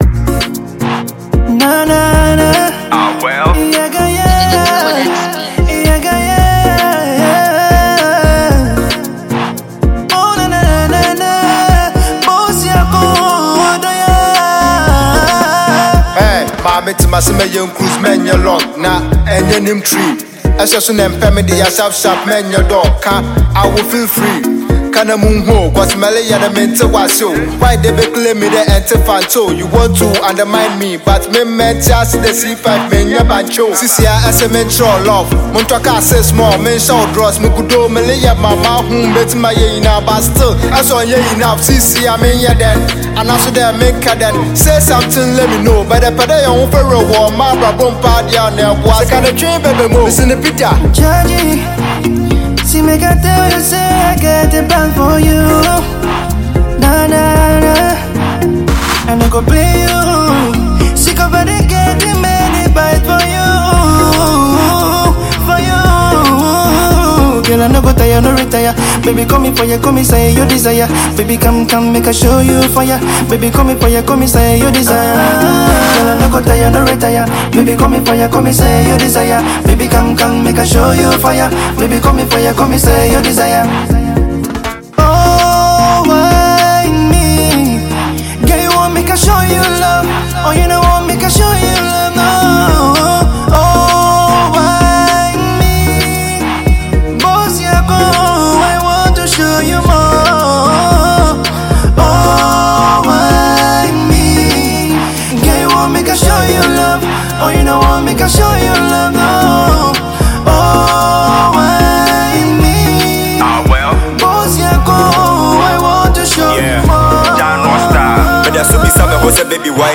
a bop and high vibing all weather tune
African rap music
Hip Life or Ghana hip hop music